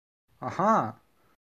Звуки человека, аха
• Качество: высокое
Немного быстрее ахакнул